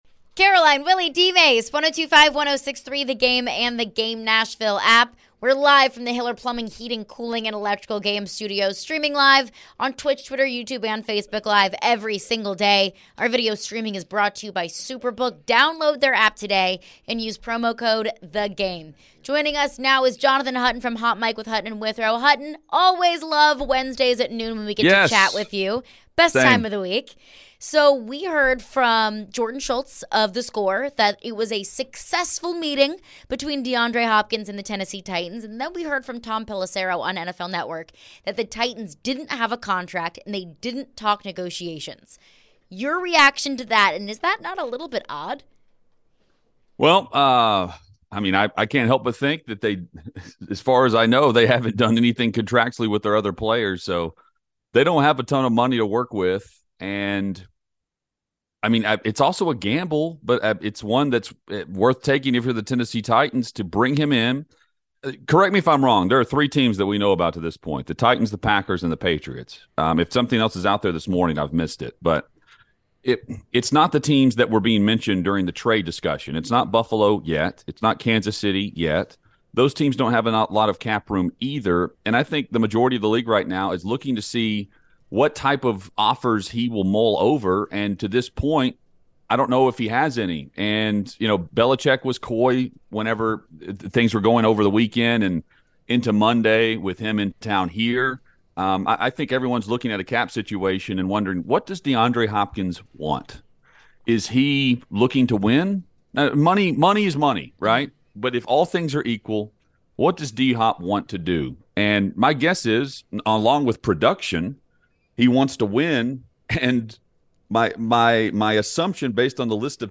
Ryan Tannehill and Mike Vrabel talk to the media about today's session of OTA's.